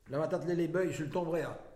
Localisation Saint-Christophe-du-Ligneron
Catégorie Locution